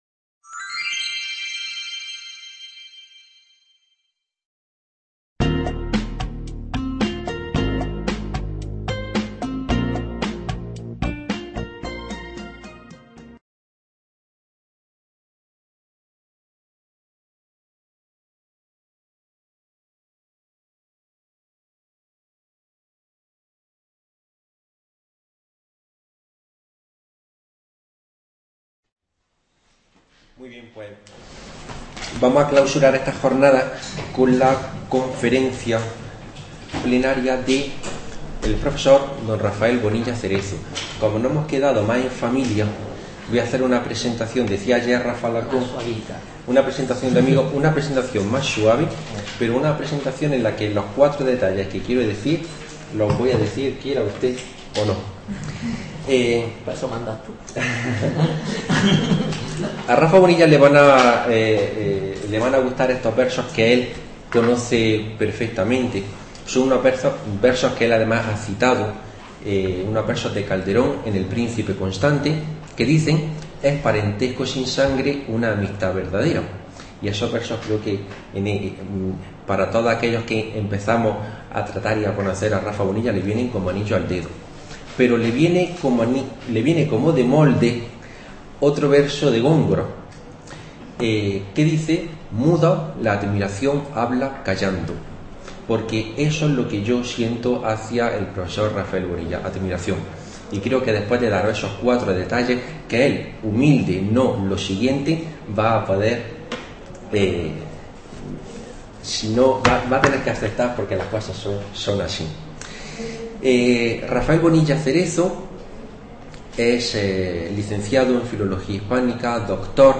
conferencia